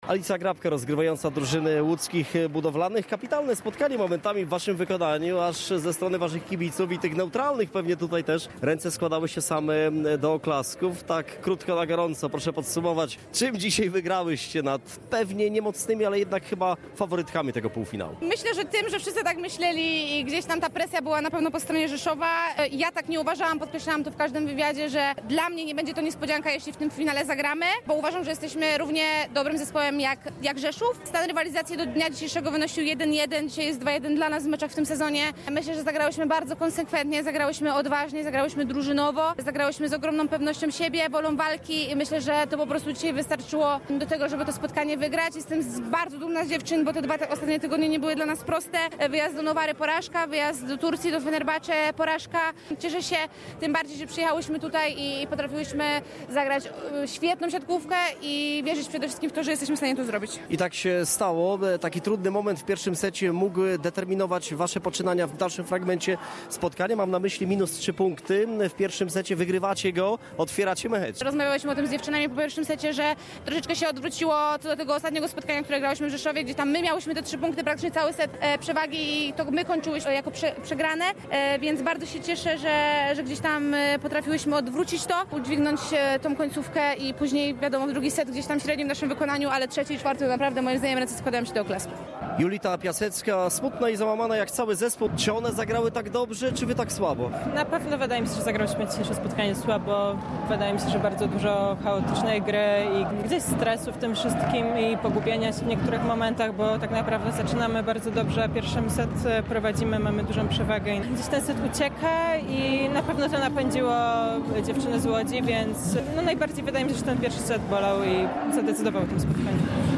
07.02-Developres-przegral-polfinal-rozmowki.mp3